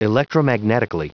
Prononciation du mot electromagnetically en anglais (fichier audio)
Prononciation du mot : electromagnetically